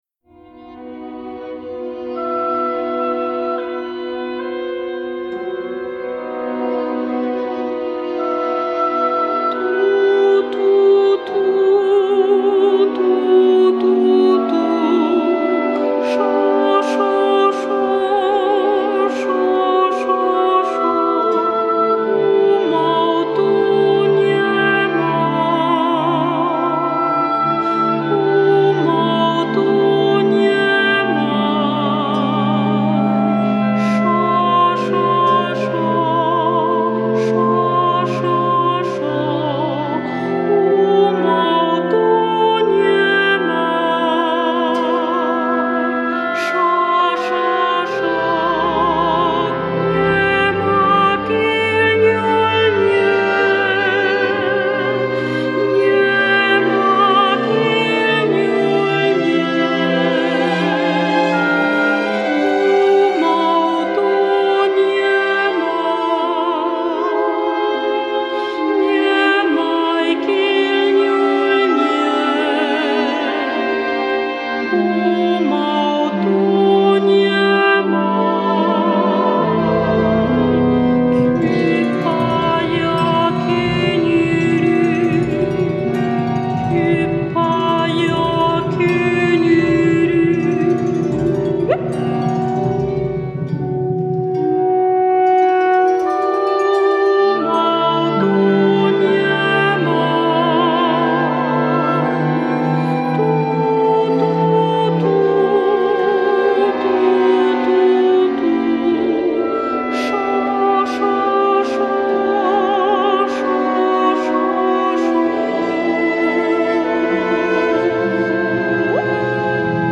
Orquesta
Música vocal